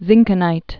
(zĭngkə-nīt)